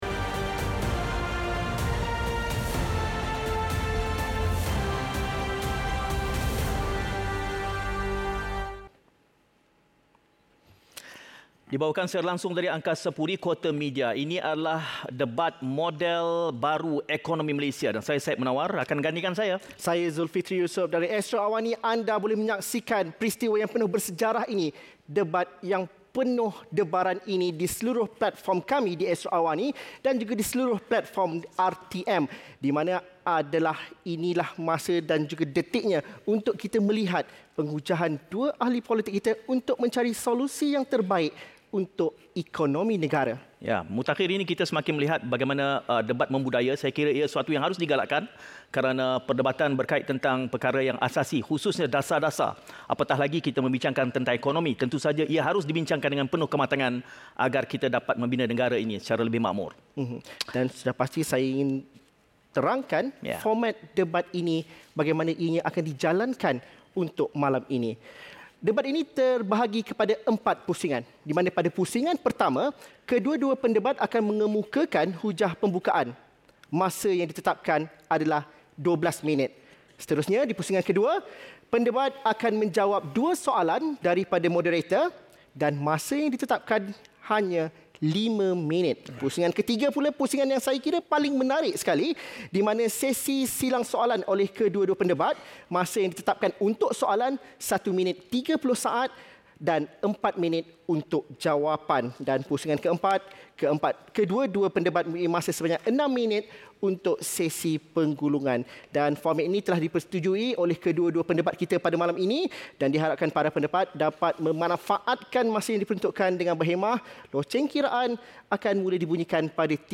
Ikuti Debat Model Baharu Ekonomi Malaysia di antara Menteri Ekonomi, Rafizi Ramli dan Ahli Parlimen Bachok, Syahir Sulaiman.